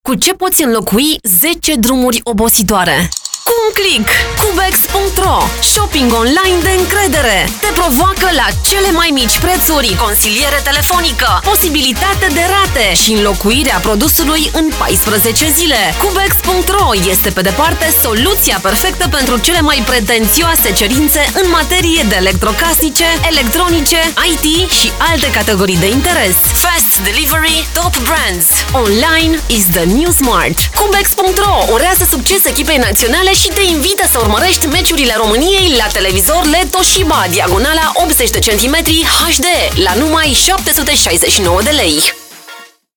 Native speaker Female 30-50 lat
Nagranie lektorskie